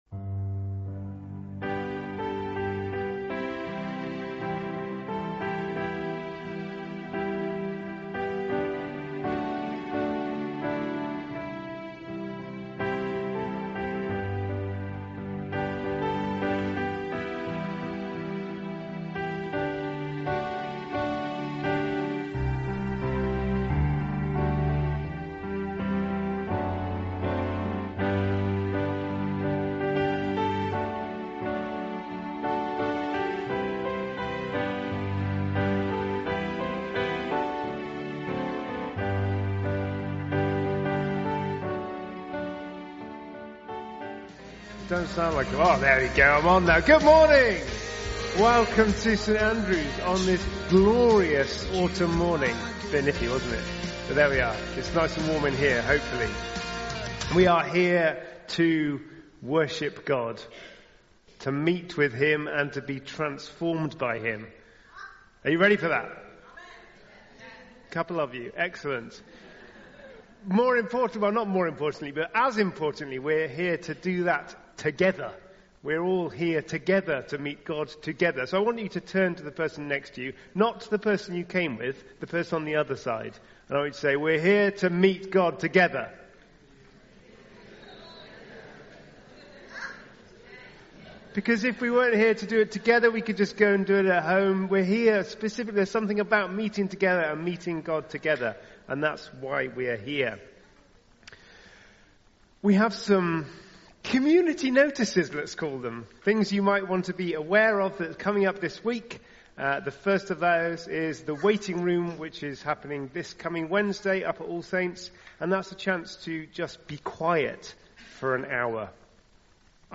Contemporary Service on 15th October 2023
Recorded at the 10:30 service on on Sunday 15th October 2023 at St Andrew’s Church, Malvern.